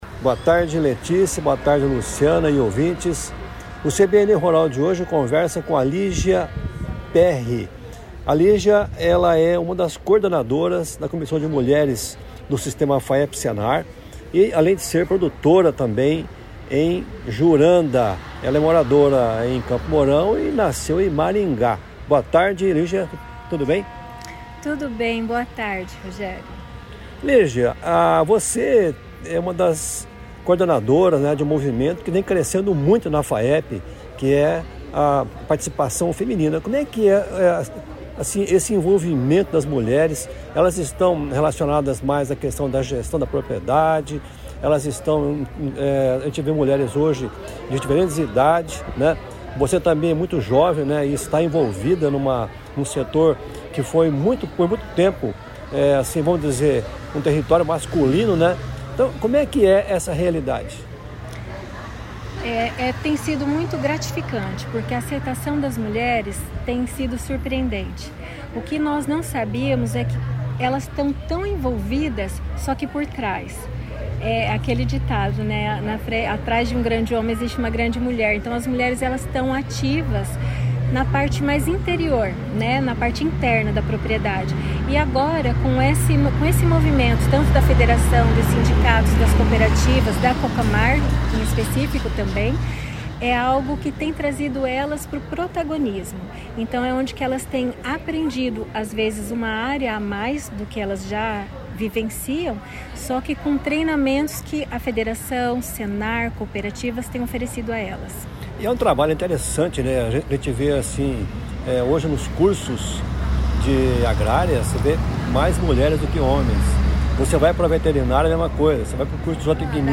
A entrevista nesta quarta-feira